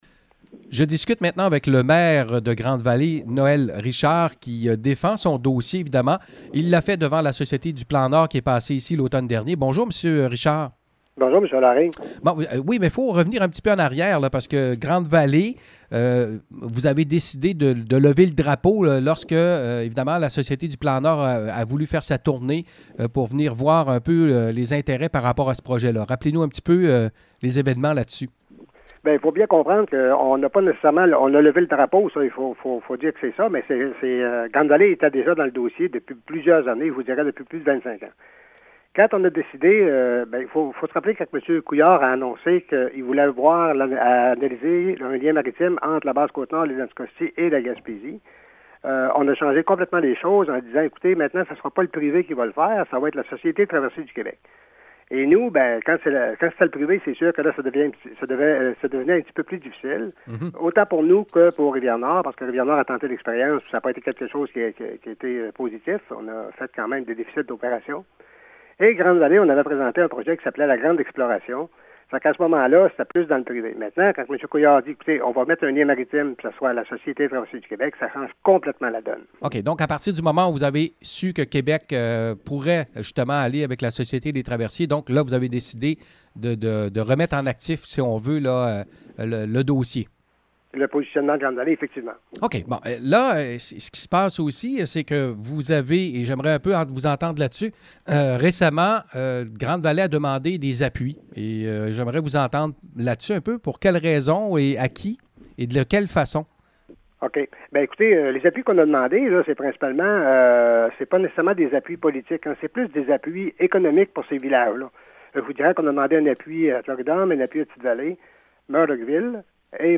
Entrevue avec le maire de Grande-Vallée, Noël Richard: